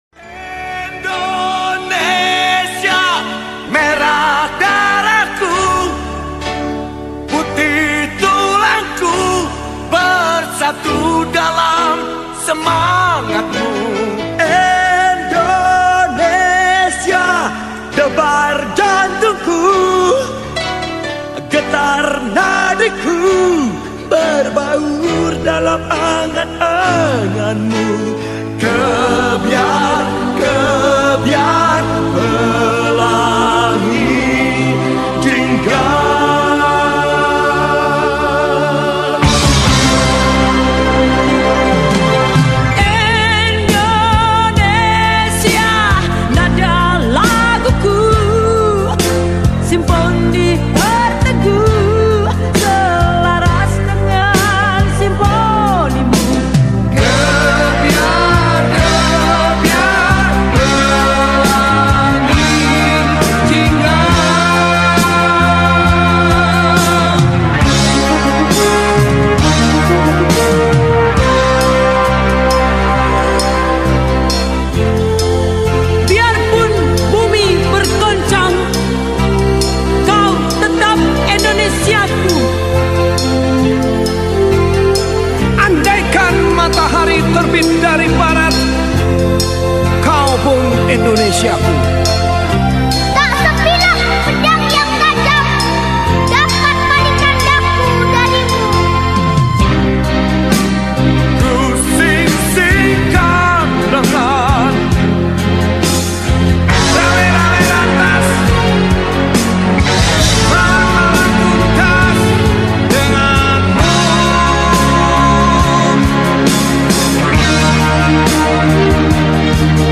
Gerak jalan Peringatan HUT Republik Indonesia Ke-80 Tahun 2025